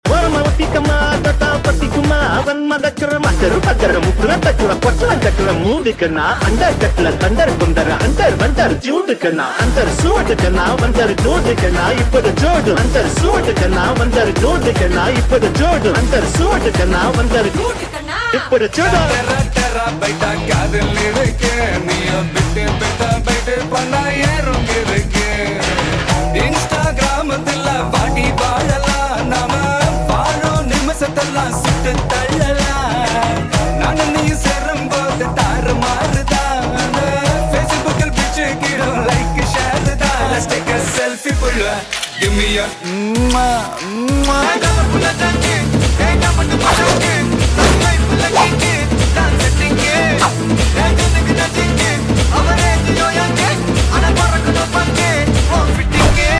pulsating